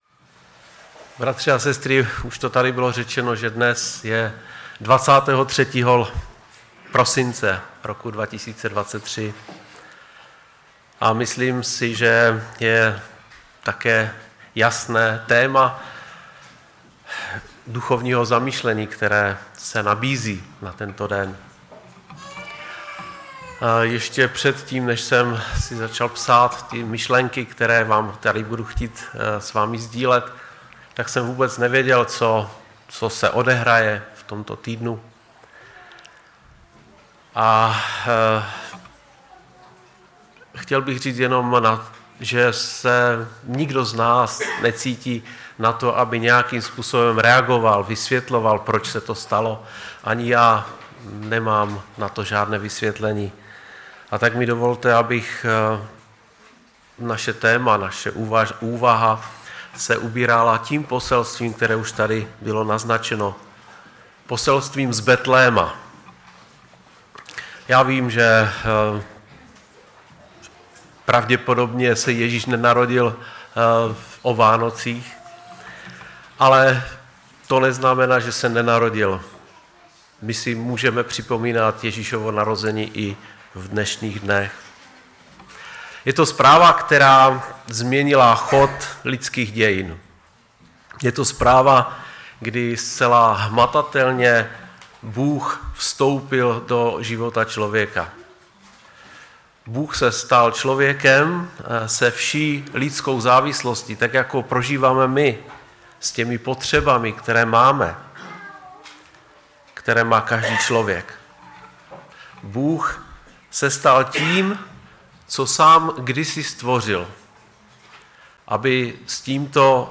ve sboře Ostrava-Radvanice.
Kázání